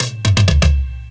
DRUMFILL14-R.wav